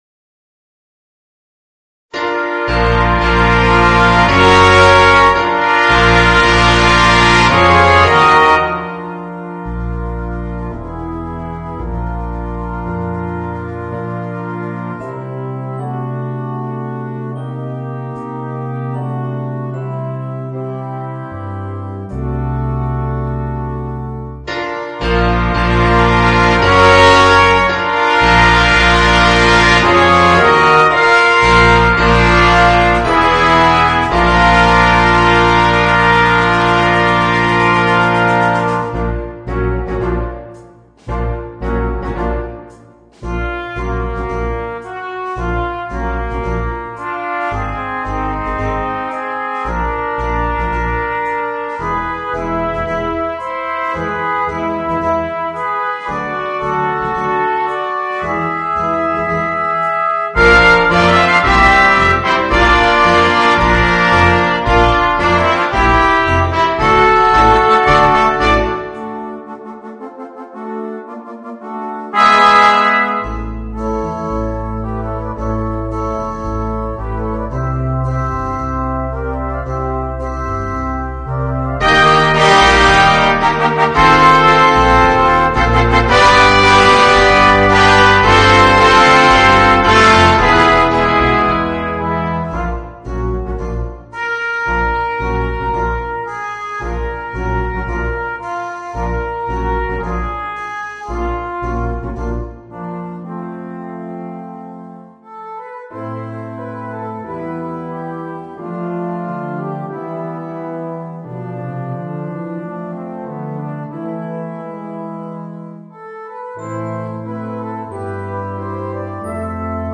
Voicing: 2 Trumpets, Horn, 2 Trombones and Drums